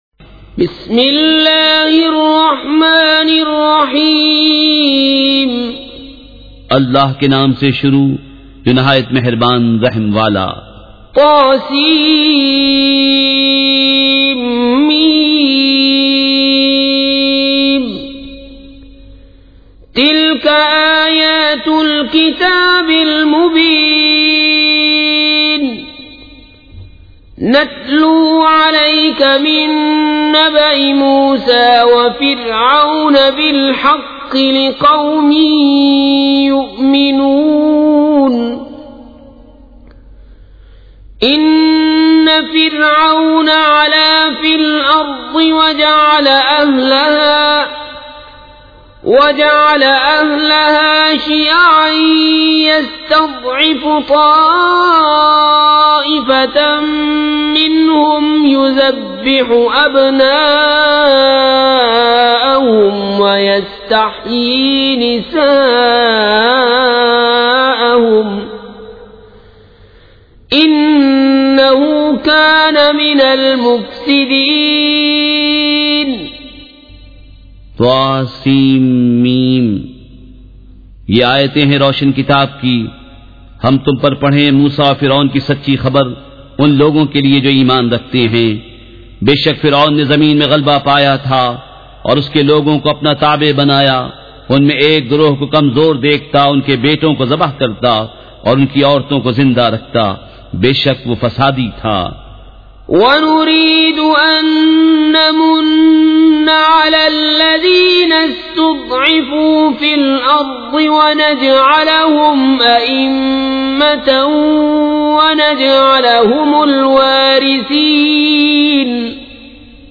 سورۃ القصص مع ترجمہ کنزالایمان ZiaeTaiba Audio میڈیا کی معلومات نام سورۃ القصص مع ترجمہ کنزالایمان موضوع تلاوت آواز دیگر زبان عربی کل نتائج 1921 قسم آڈیو ڈاؤن لوڈ MP 3 ڈاؤن لوڈ MP 4 متعلقہ تجویزوآراء